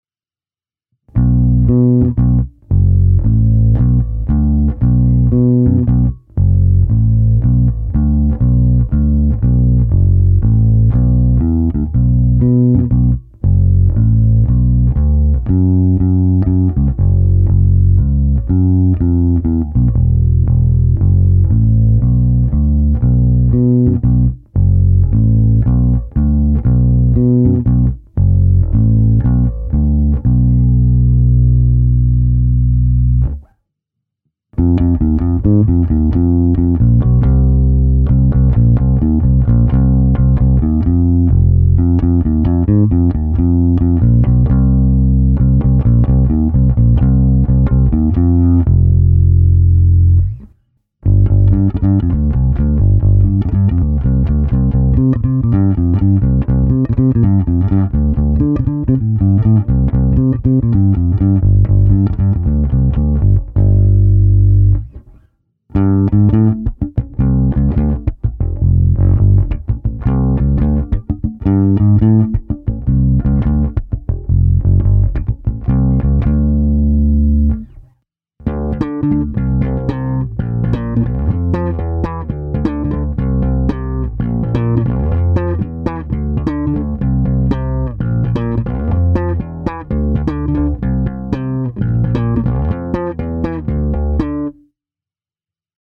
Snímač je kousavější, agresívnější, a to i přes použité hlazené struny Thomastik-Infeld Jazz Flat Wound JF344 (recenze), jinak klasický precižnovský charakter se nezapře. S nimi jsem provedl nahrávku rovnou do zvukovky.